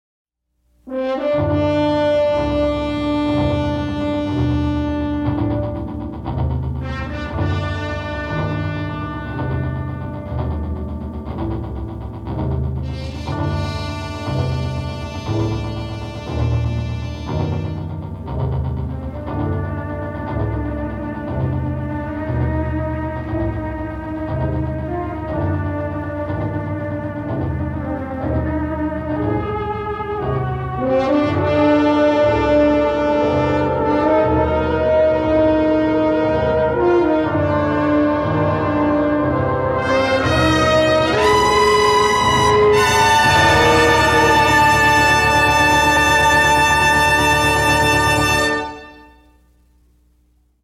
c’est souvent purement illustratif